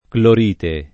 [ klor & te ]